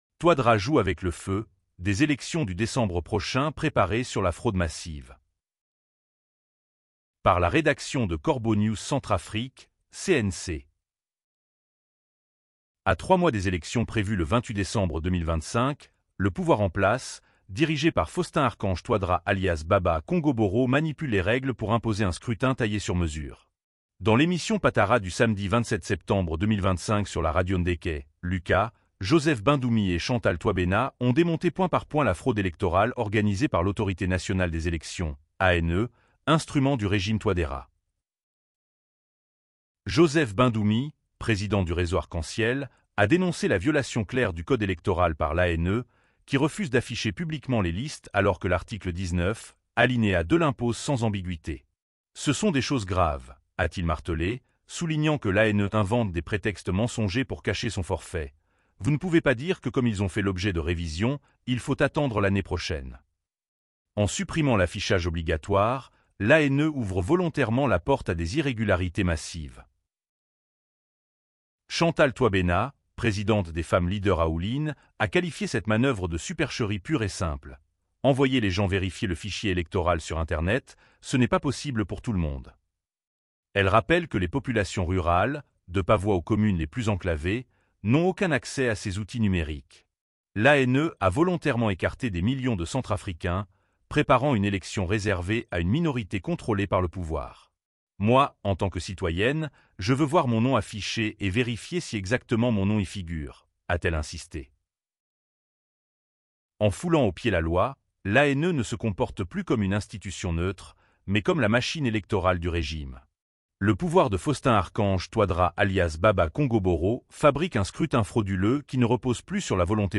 Dans l’émission Patara du samedi 27 septembre 2025 sur la radio Ndèkè -Luka